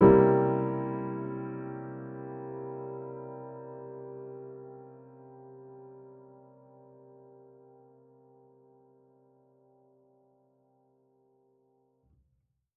Index of /musicradar/jazz-keys-samples/Chord Hits/Acoustic Piano 1
JK_AcPiano1_Chord-C7b9.wav